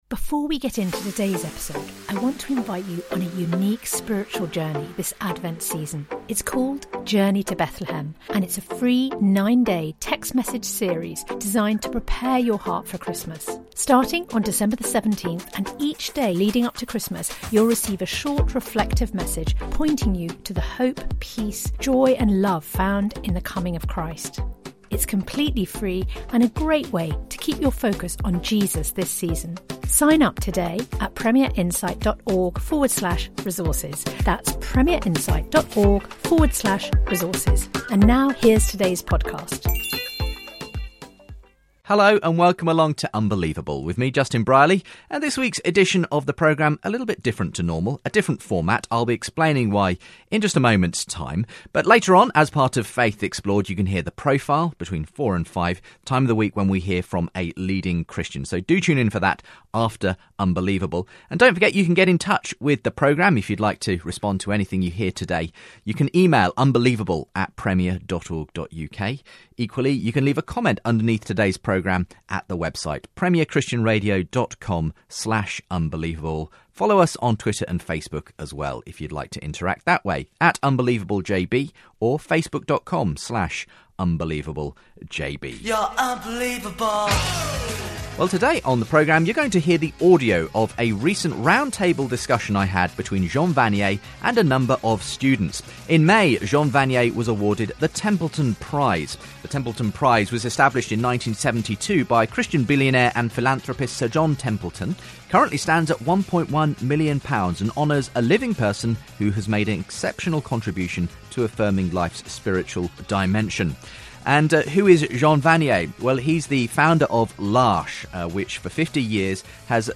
Jean Vanier & Students roundtable discussion